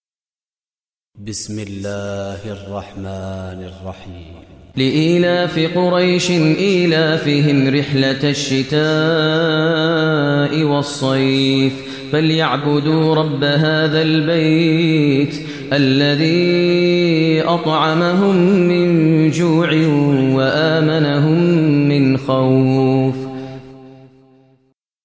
Surah Quraish Recitation by Maher al Mueaqly
Surah Quraish, listen online mp3 tilawat / recitation in Arabic recited by Imam e Kaaba Sheikh Maher al Mueaqly.